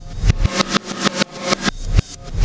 Black Hole Beat 22.wav